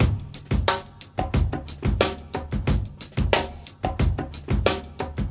Drum Loops
FUNK
Straight / 90.084 / 2 mes